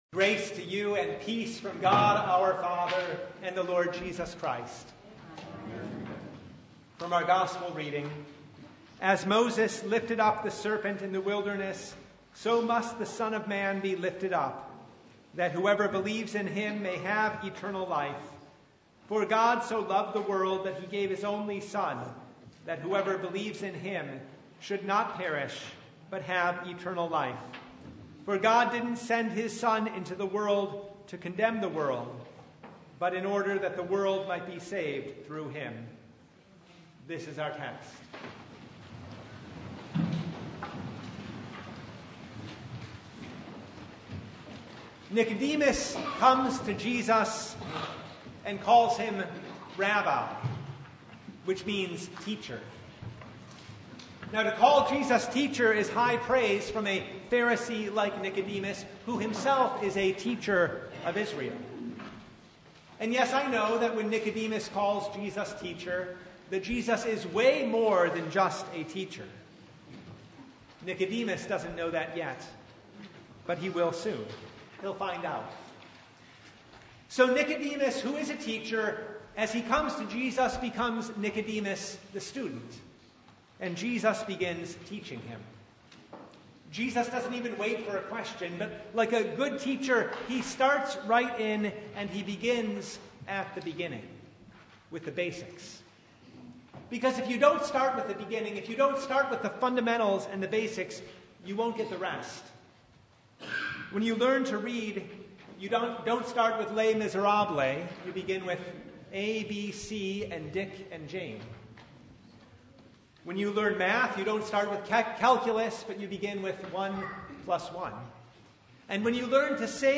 John 3:14-17 Service Type: Sunday Bible Text